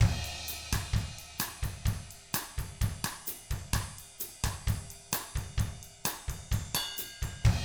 129BOSSAT1-R.wav